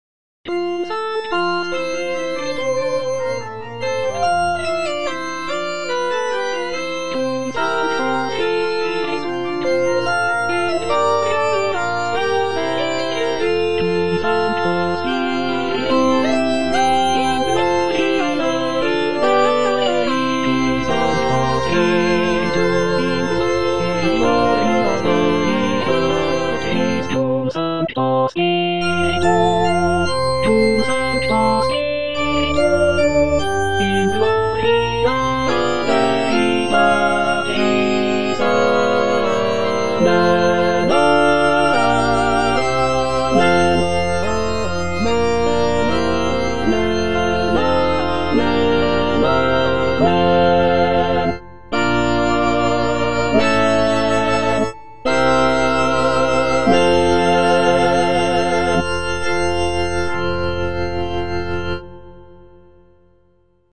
T. DUBOIS - MESSE IN F Cum sancto spiritu (All voices) Ads stop: auto-stop
"Messe in F" is a choral work composed by Théodore Dubois in the late 19th century. It is a setting of the traditional Catholic Mass text in the key of F major. The piece is known for its lush harmonies, intricate counterpoint, and lyrical melodies.